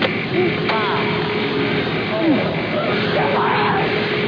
The quality is kept to a minimum because of webspace limitations.